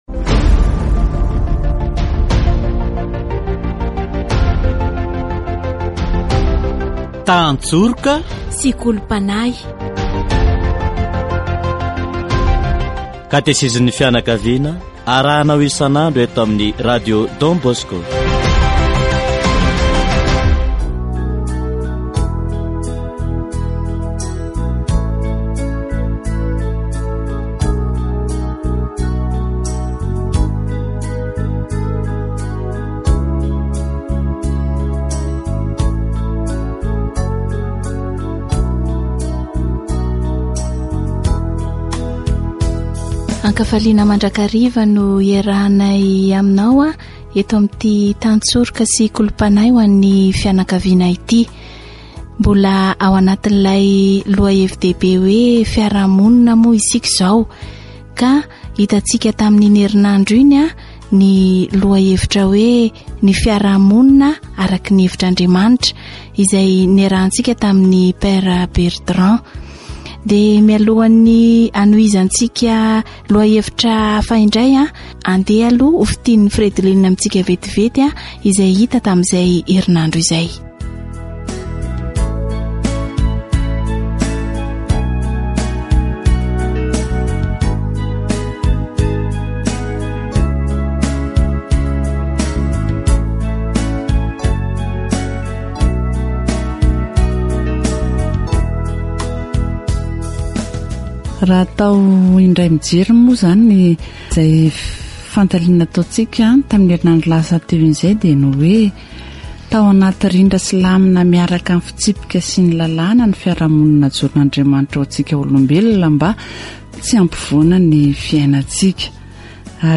Catechesis on politics according to the opinion of the Church